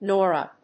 音節No・ra 発音記号・読み方
/nˈɔːrə(米国英語), ˈnɔ:rʌ(英国英語)/